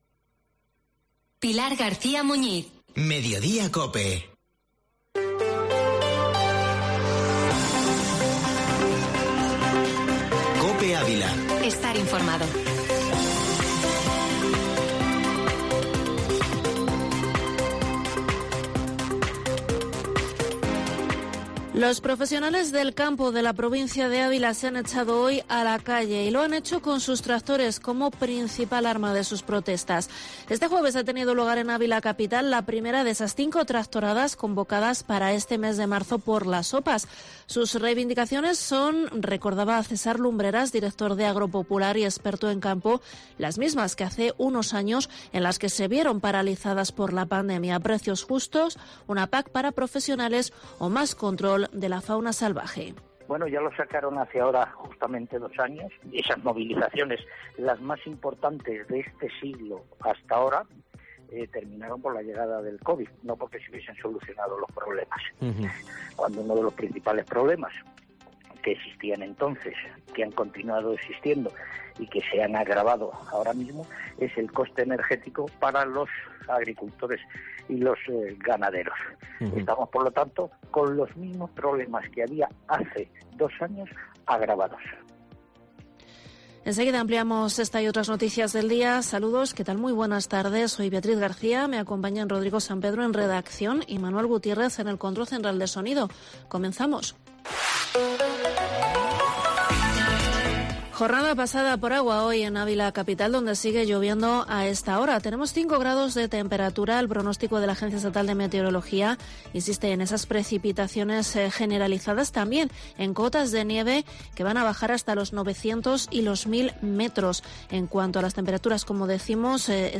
Informativo Mediodía COPE en Ávila